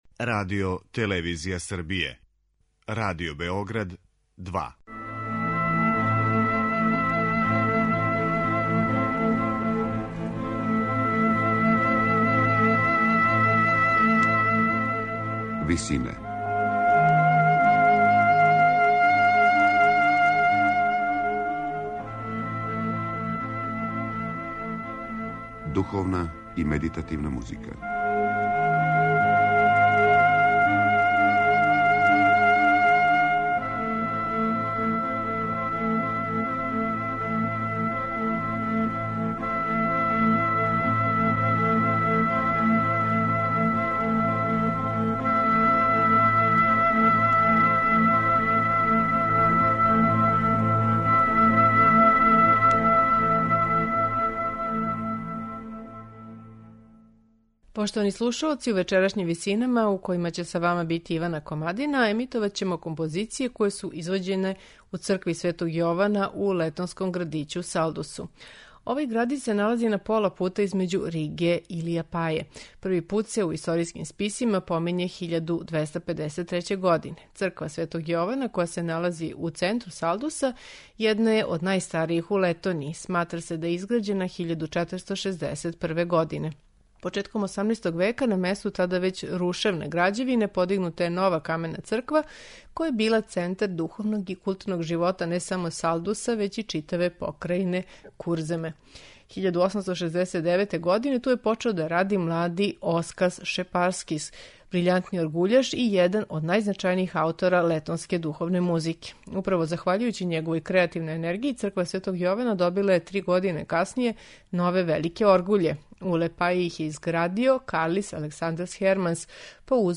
Eмитоваћeмо духовне композиције летонских аутора које су извођене у цркви Светог Јована у градићу Салдусу, у Летонији.
Слушаћете их у интерпретацији хора дечака музичке школе Jazpes Medins.